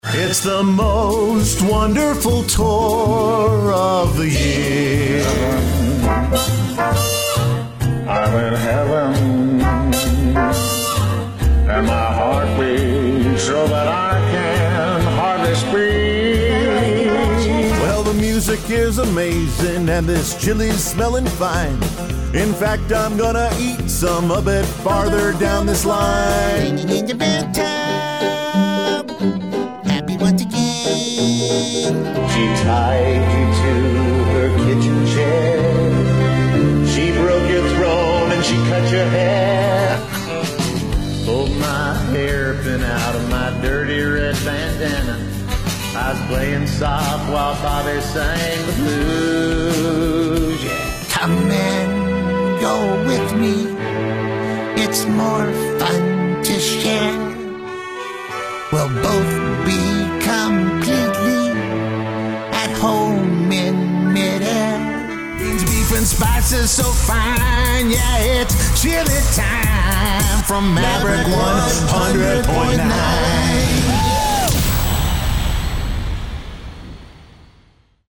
Young Adult, Adult
standard us | natural
singer